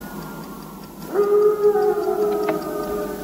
• wolf howling sound effect 2.ogg
[wolf-howling-sound-effect]-3_7ov.wav